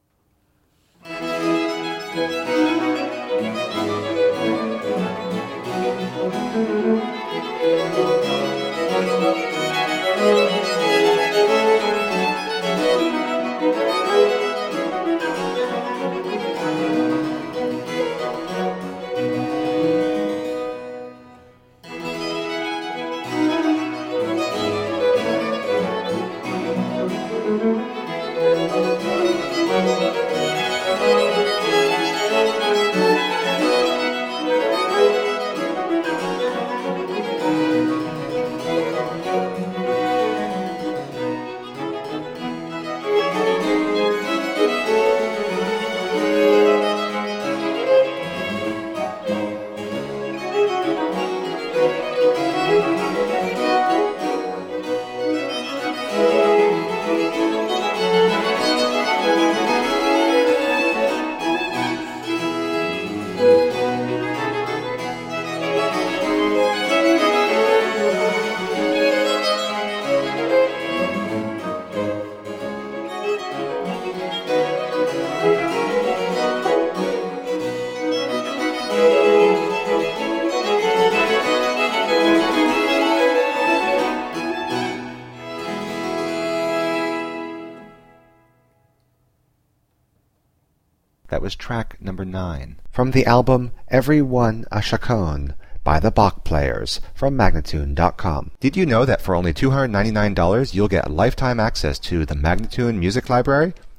Baroque instrumental and vocal gems.